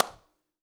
Clap10.wav